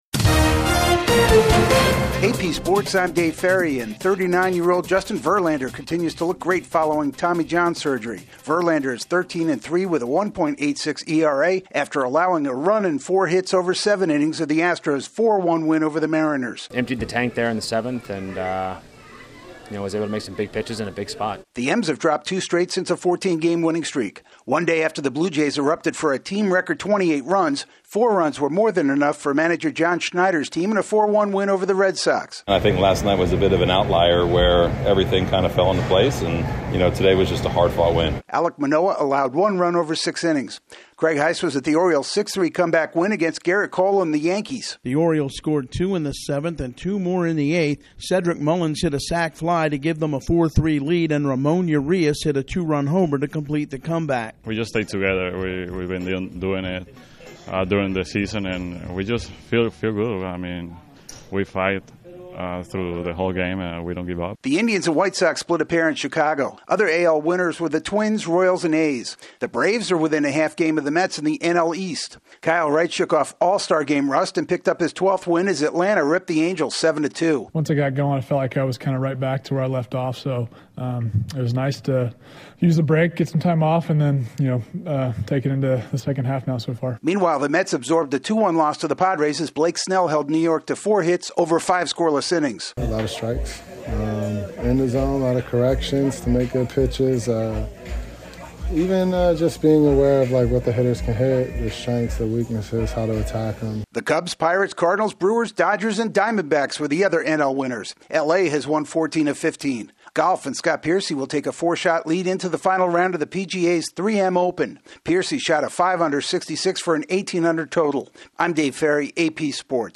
The Astros get another gem from their oldest hurler, the Blue Jays top the Red Sox again, the Orioles double up the Yankees, the Braves inch closer to first place and Scott Piercy leads this weekend's PGA event in Minnesota. AP correspondent